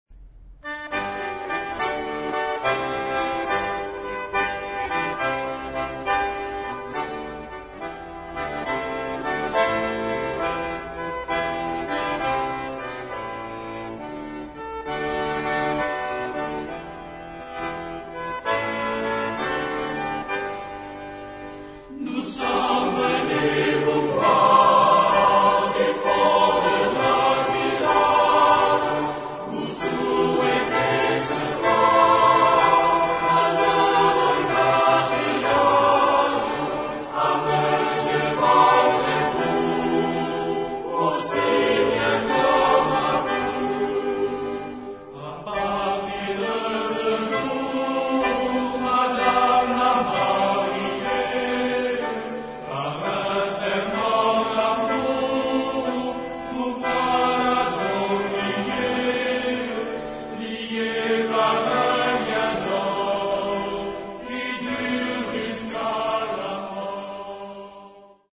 Género/Estilo/Forma: Profano ; Popular
Tipo de formación coral: SATB  (4 voces Coro mixto )
Tonalidad : la menor